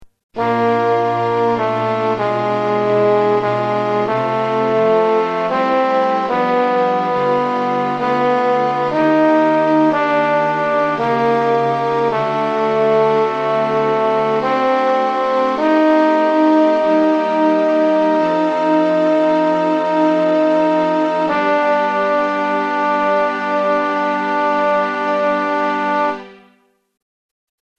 Key written in: G Minor
Type: Other male